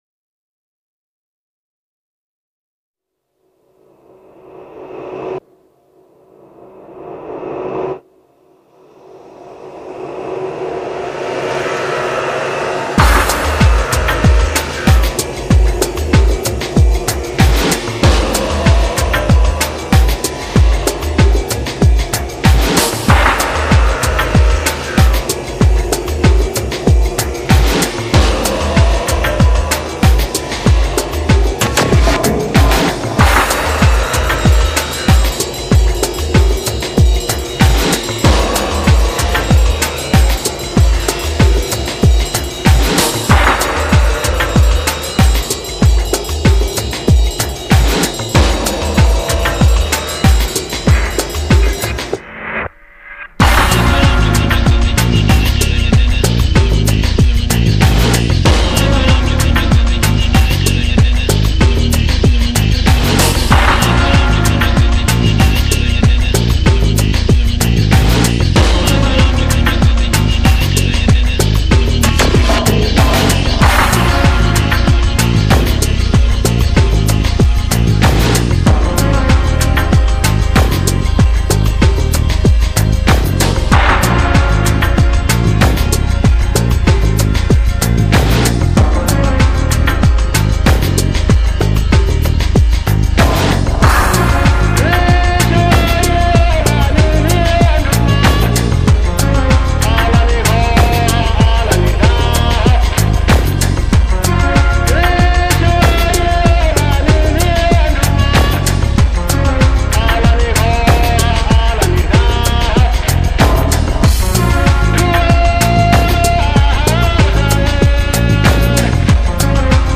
一张令你听后感到清新，宁静，忘我，释放的迷幻发烧音乐天碟。.
融合了中东迷幻与西藏的神秘感，穿插了佛教的宁静，加入了现在.
一流的录音效果，是今世纪不可错过的经典唱片，试音必备。　　.